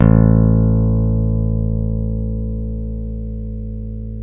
Index of /90_sSampleCDs/Roland L-CDX-01/BS _Rock Bass/BS _Stretch Bass